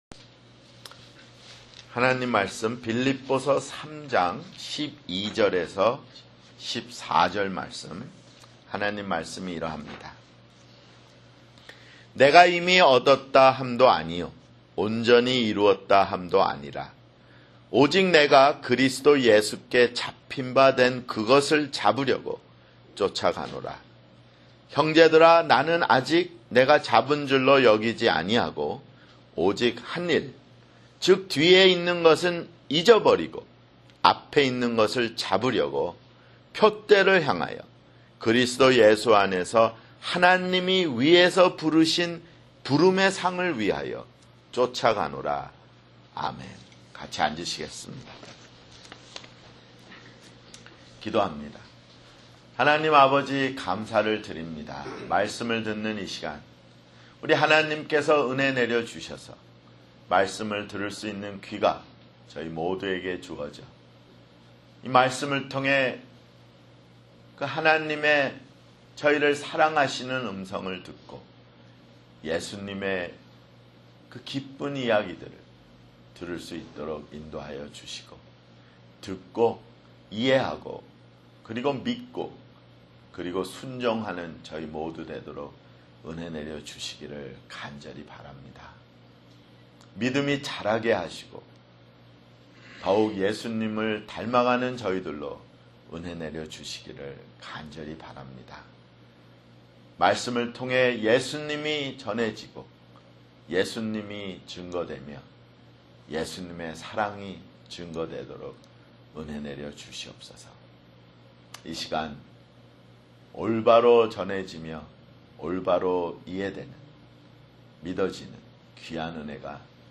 [주일설교] 빌립보서 (47)